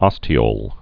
(ŏstē-ōl)